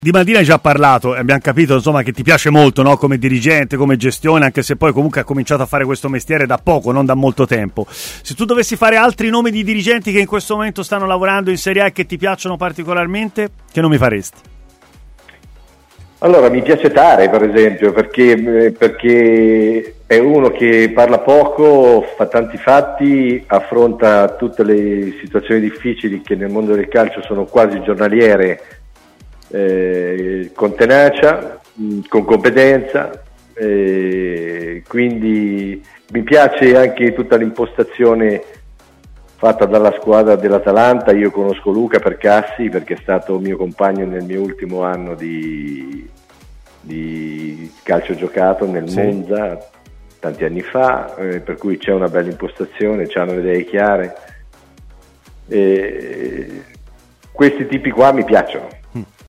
Intervenuto ai microfoni di TMW Radio, l'ex dirigente dell'Inter ha espresso parole di stima nei confronti del direttore sportivo Tare.